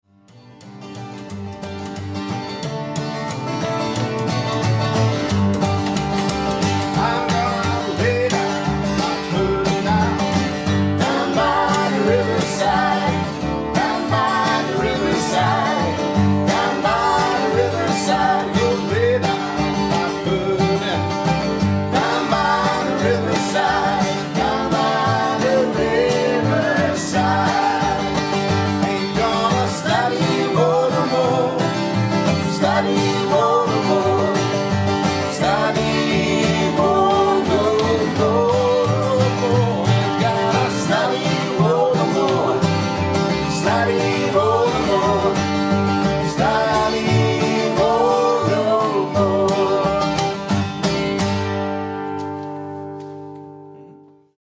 Americana-Roots-Music
Soul trifft auf kraftvoll swingendes Geigenspiel.
Vocals, Guitar, Fiddle, Mandolin
Bass, Vocals
Vocals, Guitar, Kazoo, Percussion